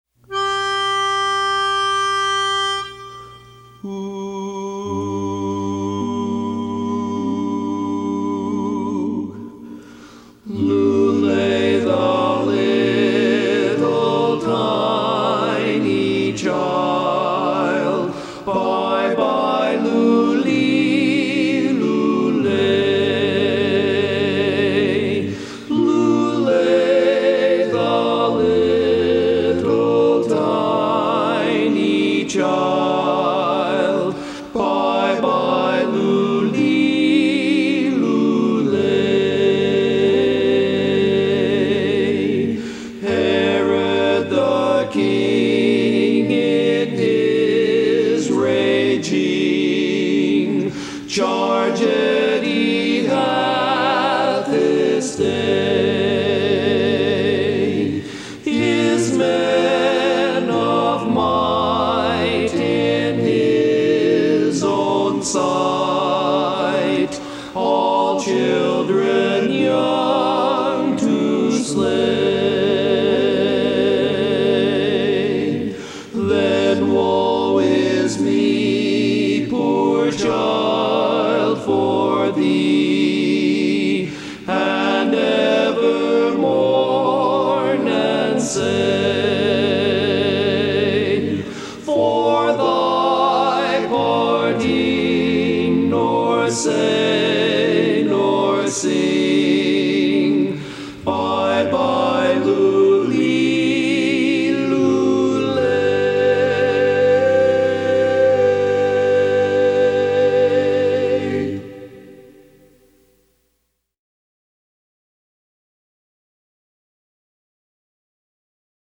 Christmas Songs
Barbershop
Lead